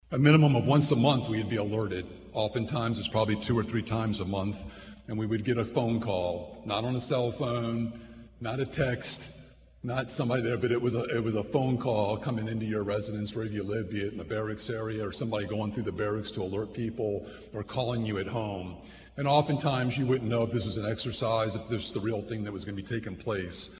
The annual parade and Ceremony of Honor recognized “Veterans of the Cold War.”